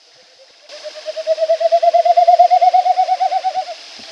Snipe winnowing (356) (Compare to Curly)
Snipe.wav